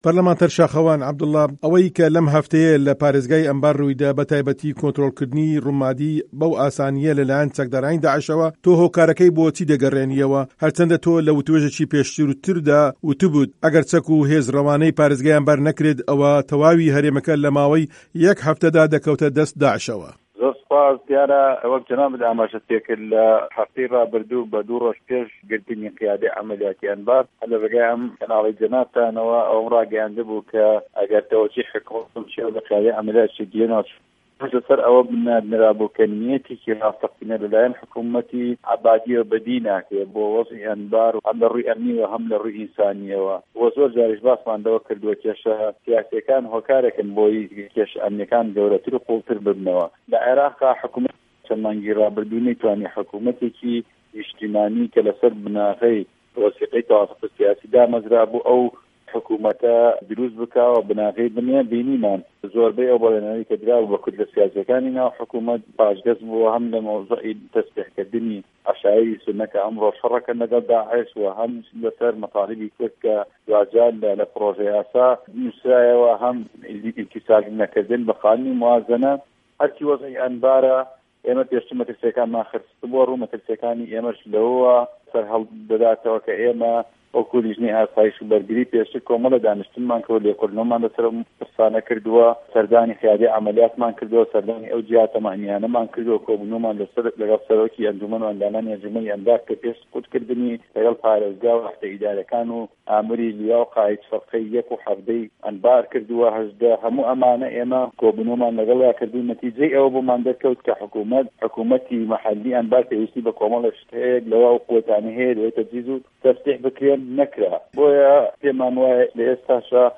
وتووێژی شاخه‌وان عه‌بدوڵڵا